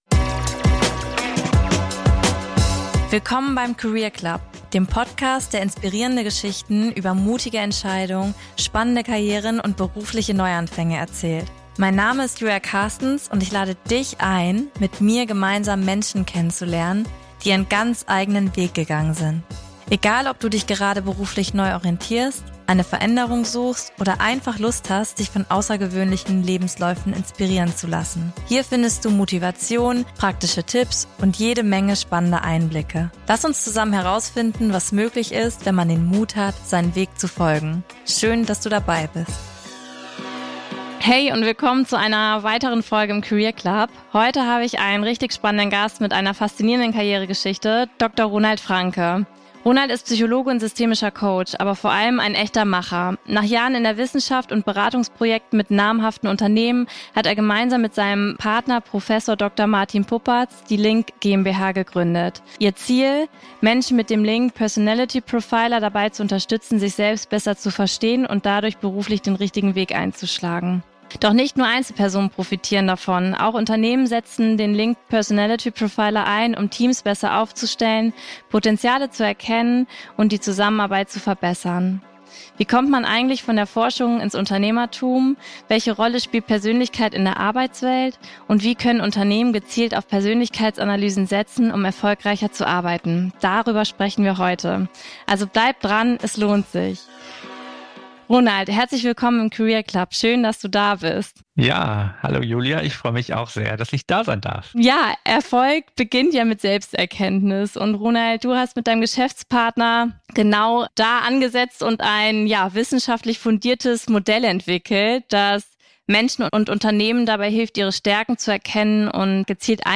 Ein Gespräch über Selbstkenntnis, Entscheidungsfindung und die Frage: Wie finden wir wirklich den richtigen Karriereweg?